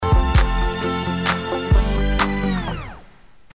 Нотки ля и ля-диез первой откавы (по музыке) при переходах от pp к следующим двум слоям и до четвертого fff зацепляют соседнюю малую секунду.